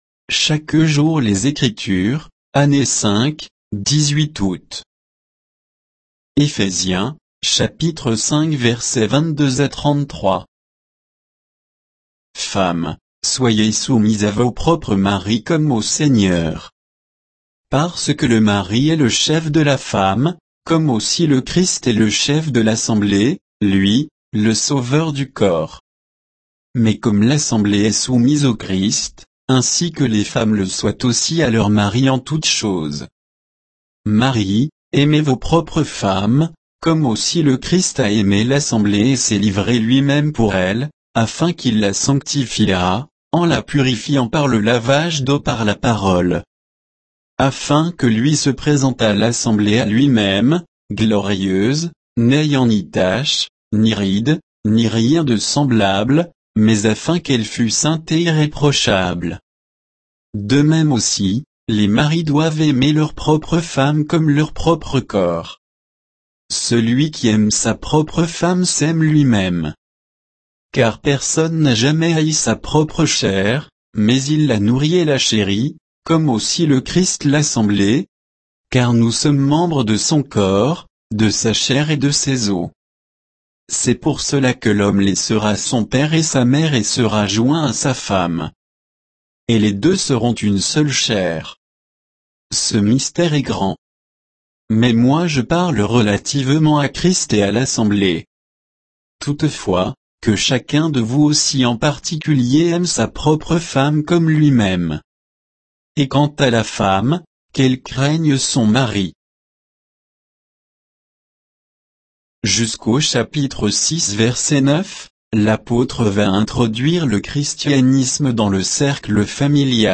Méditation quoditienne de Chaque jour les Écritures sur Éphésiens 5